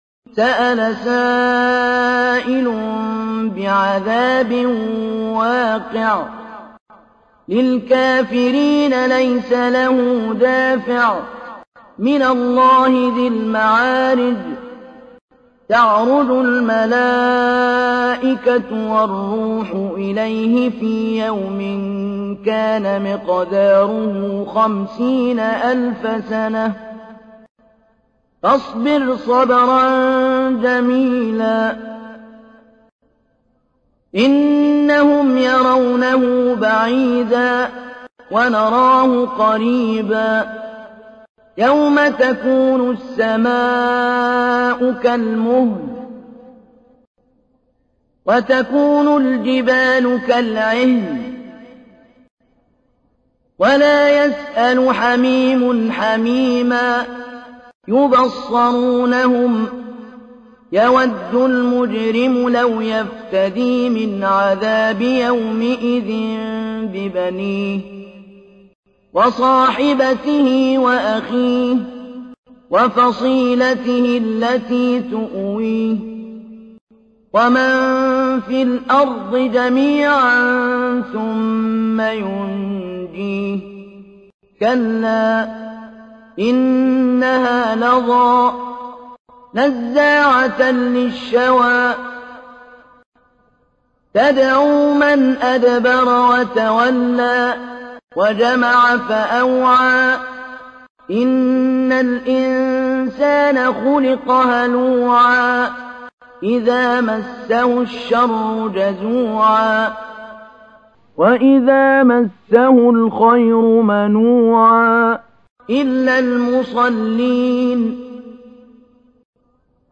تحميل : 70. سورة المعارج / القارئ محمود علي البنا / القرآن الكريم / موقع يا حسين